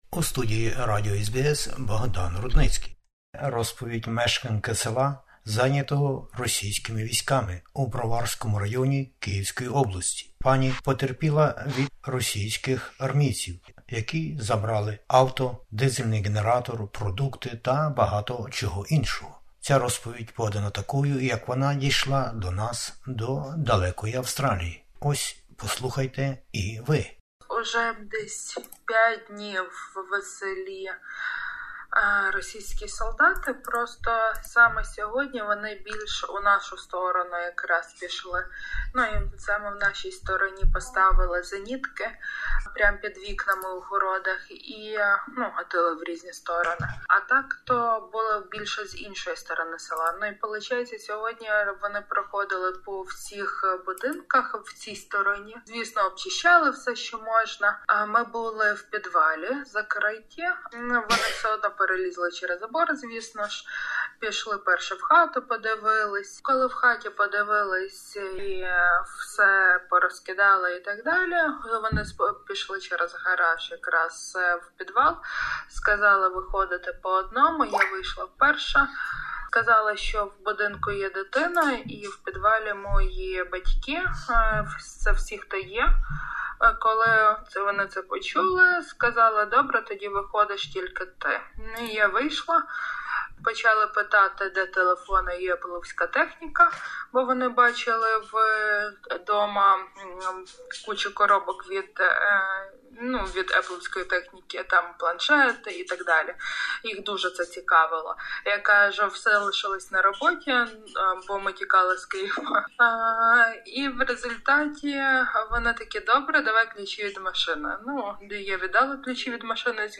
Війна в Україні: свідчення жінки із Київщини